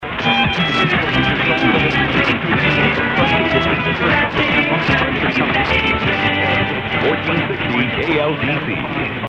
recording Clear signal